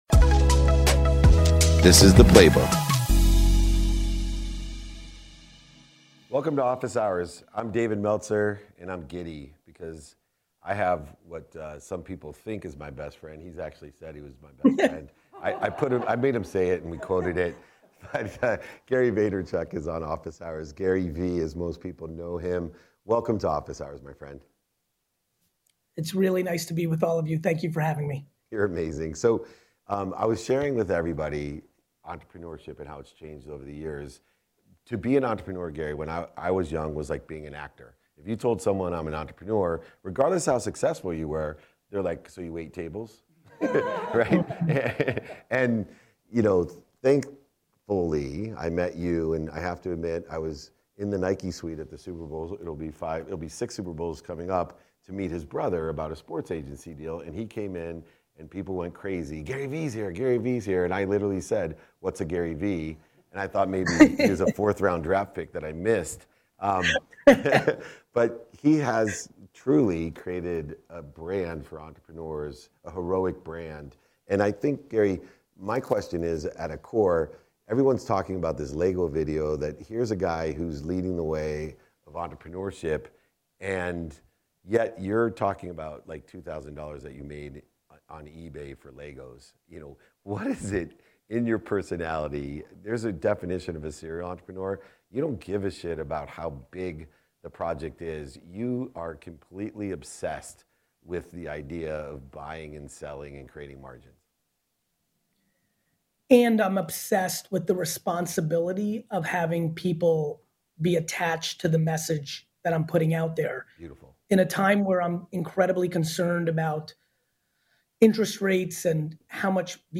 On today’s episode, we had the privilege of talking with Gary Vaynerchuk, one of the world’s most successful entrepreneurs and motivational speakers, on Season 3 of Office Hours. During our insightful dialogue. Gary touched on his obsession with people being attached to the message he’s amplifying about entrepreneurship, balancing his career with his kids and family, and the future of the NFT market.